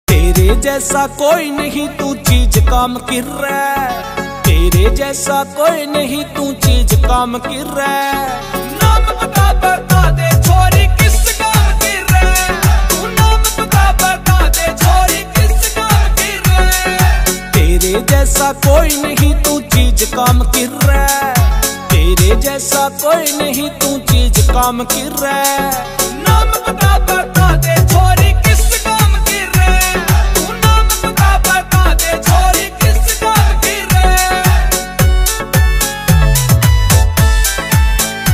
Haryanvi Song